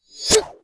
swordman_attack3.wav